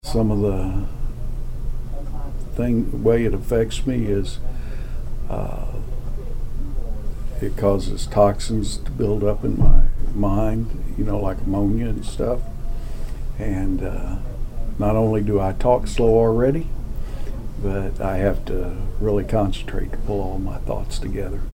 “Some of the way it affects me is it causes toxins to build up in my mind, like ammonia,” said Cookson, his normally strong voice quieted with fatigue.